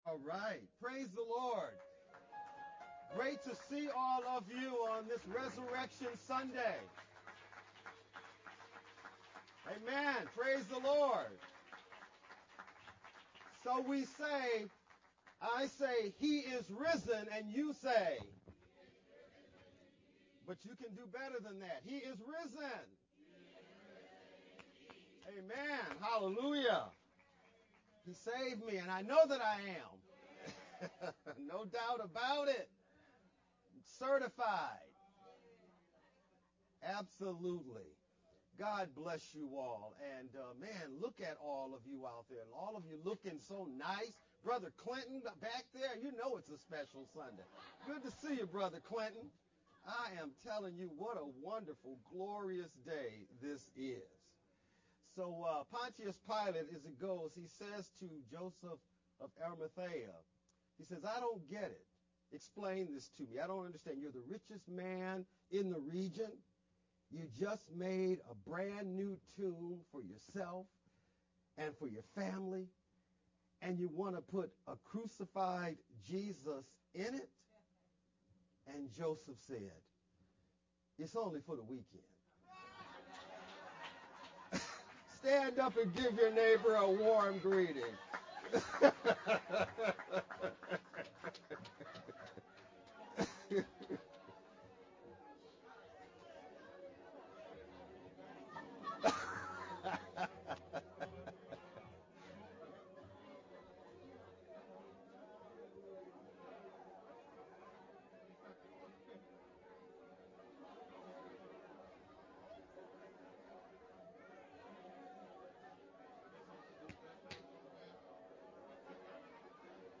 Easter-service-sermon-only-Mp3-CD.mp3